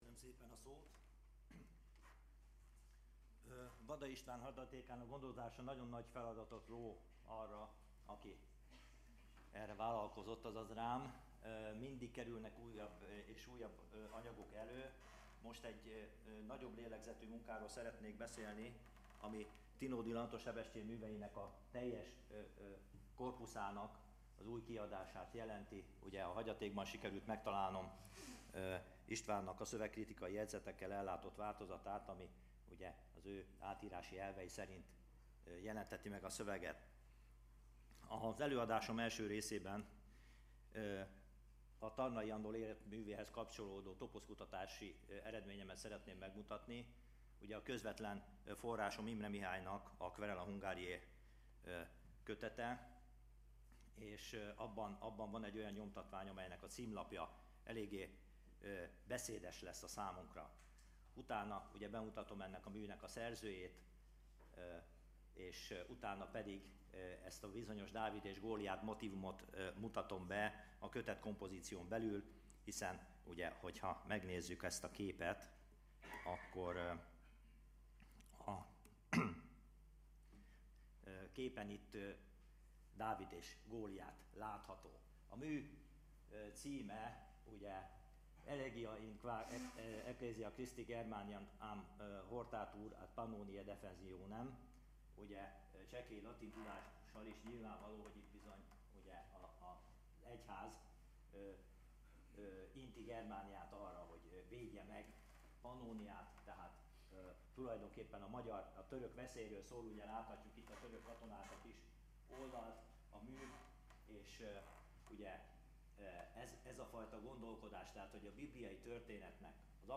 Előadásom két részre oszlik.